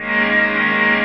CHRDPAD040-LR.wav